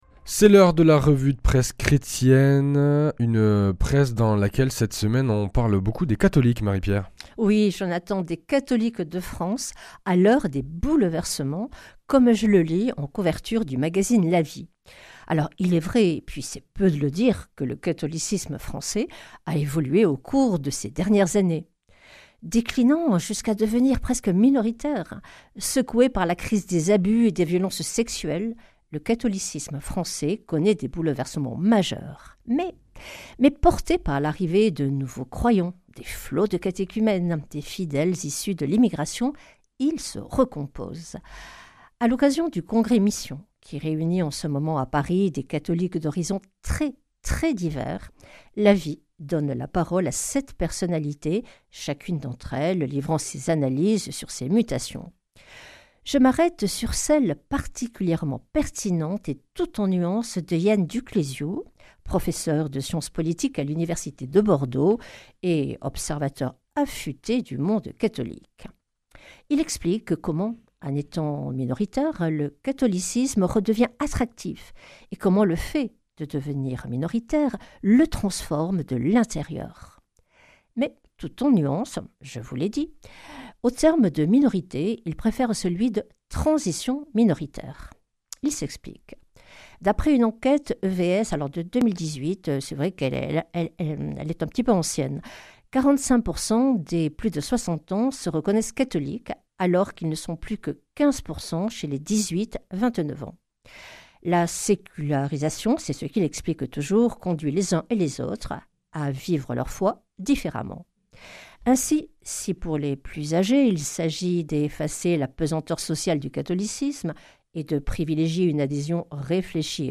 Revue de presse
Une émission présentée par
Journaliste